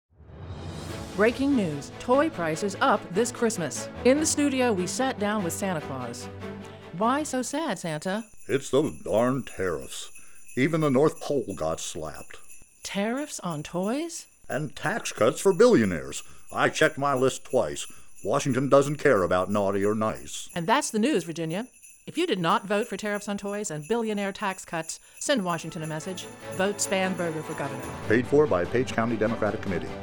Radio Ads